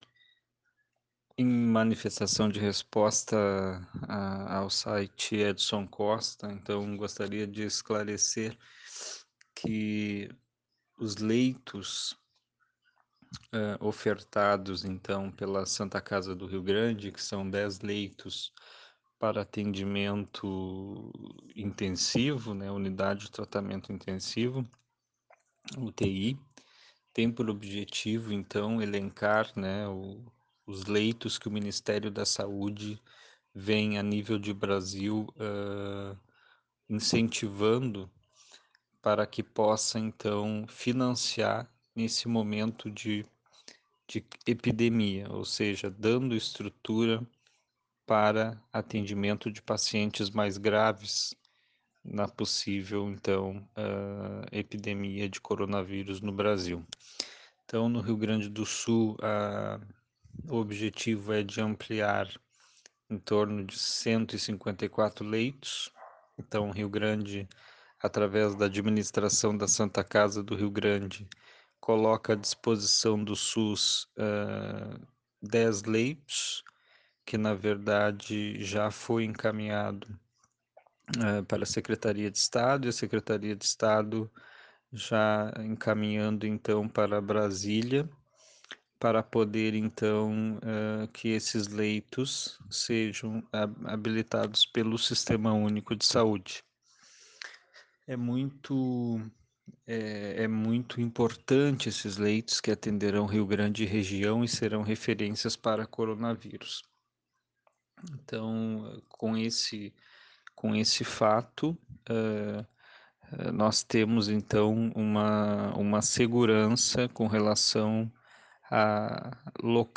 Clique no link abaixo para ouvir o secretário da Saúde do município, Maicon de Barros Lemos, falando sobre a pandemia que preocupa o mundo.